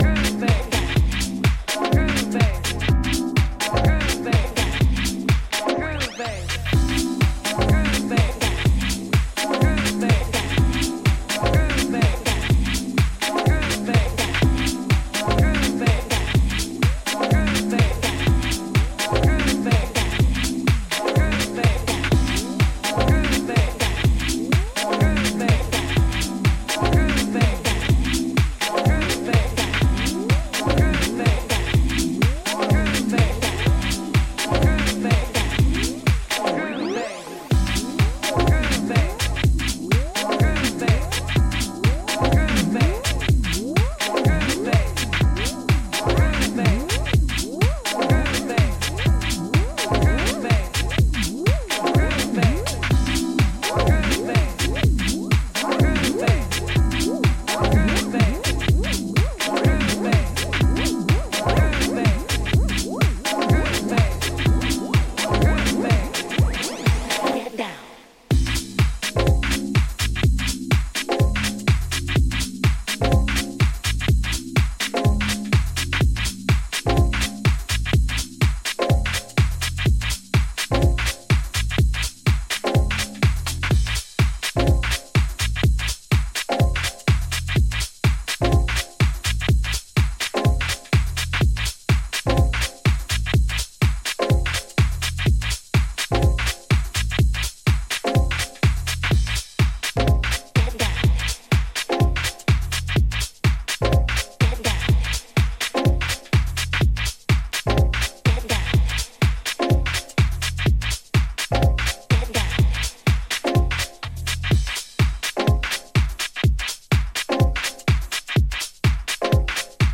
deep house